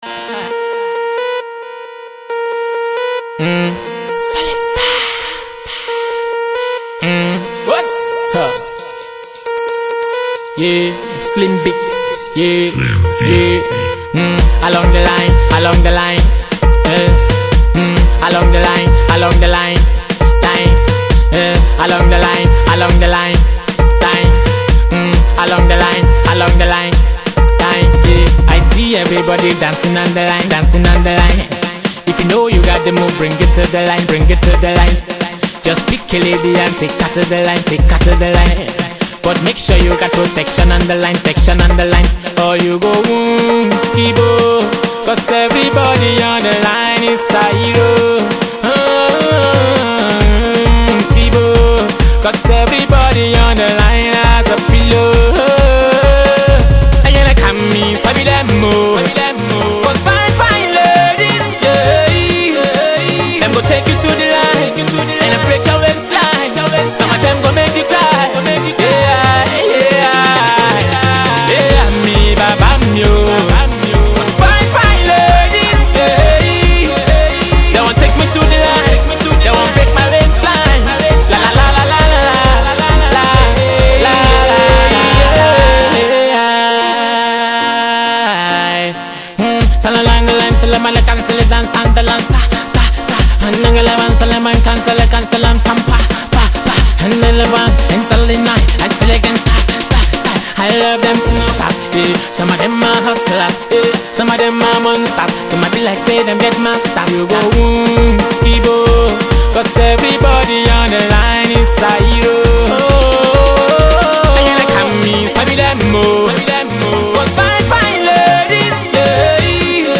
uptempo Afro-Dance track
Azonto-inspired
sweet and infectious dance tune